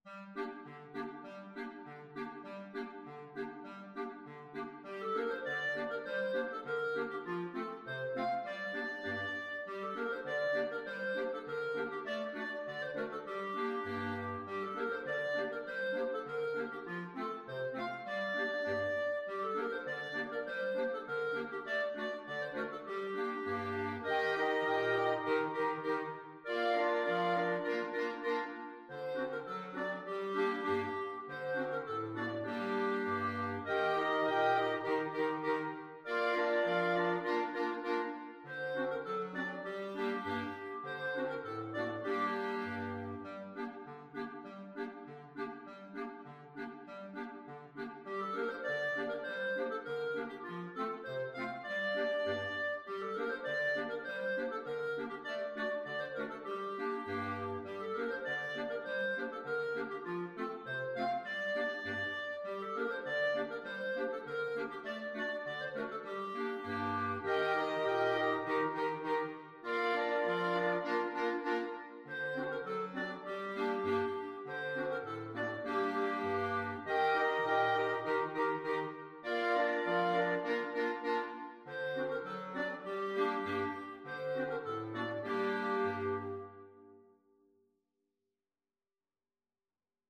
La Mariposa Free Sheet music for Clarinet Choir
World South America Bolivia
Clarinet 1 Clarinet 2 Clarinet 3 Clarinet 4 Bass Clarinet
Key: G minor (Sounding Pitch)
Time Signature: 4/4
Tempo Marking: Moderato